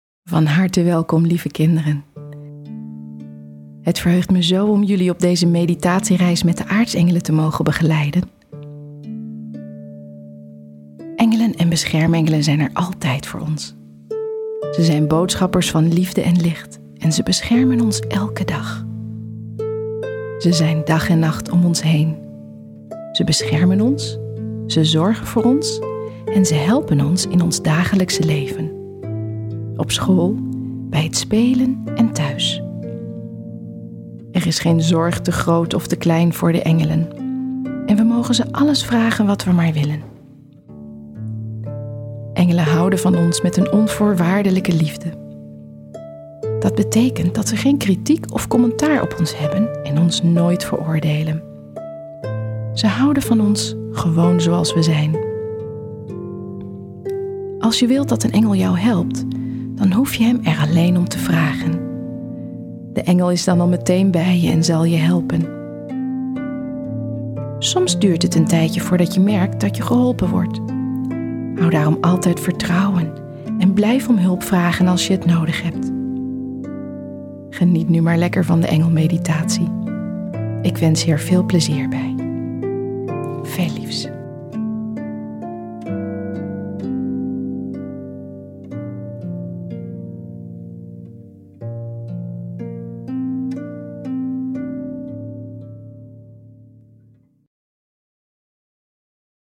3 meditaties voor kinderen
Luisterboek
De drie sprookjesachtige meditaties worden ondersteund door betoverende muziek, die de therapeutische werking van de meditaties versterkt.